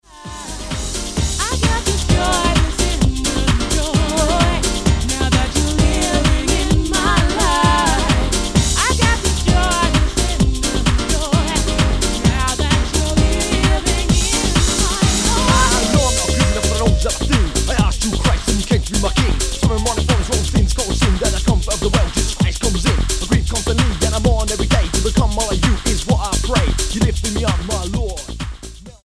Dance/Electronic
Style: Dance/Electronic Approach: Praise & Worship